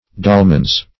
Dolman \Dol"man\ (d[o^]l"man), n.; pl. Dolmans. [Turk.